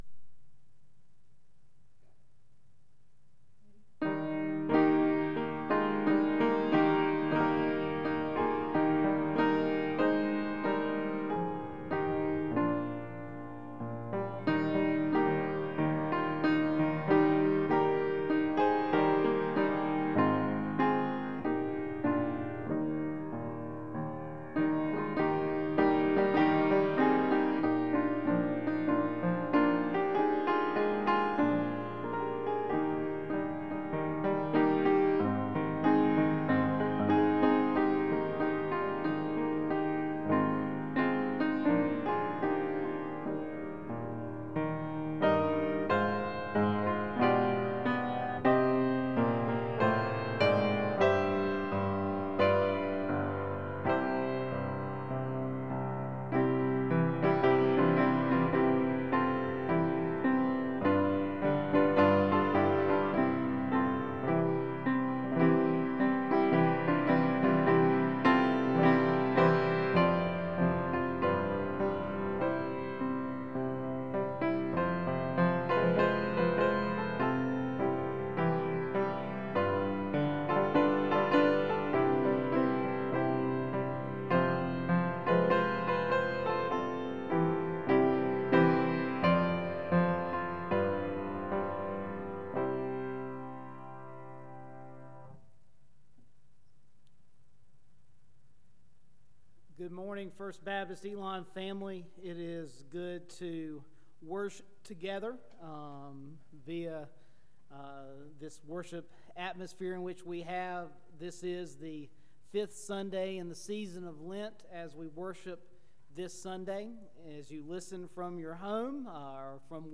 John 11:1-46 Service Type: Morning Bible Text